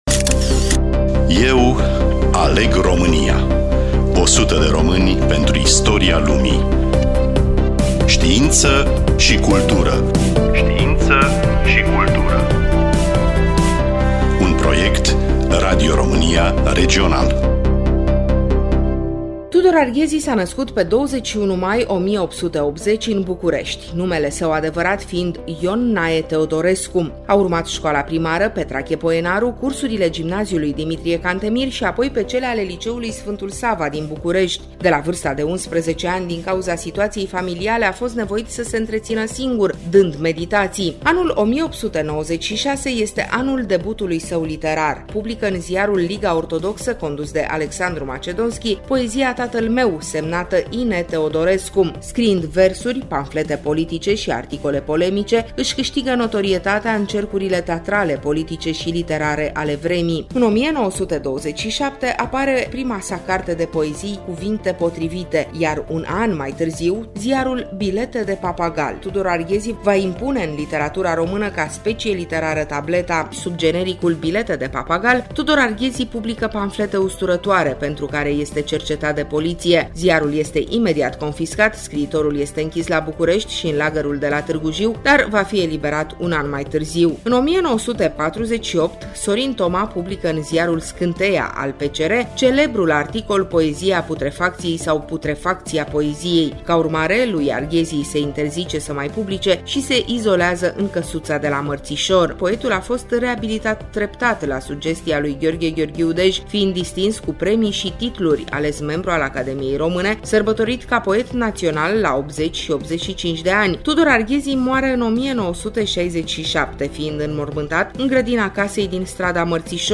Studioul: Radio Romania Oltenia-Craiova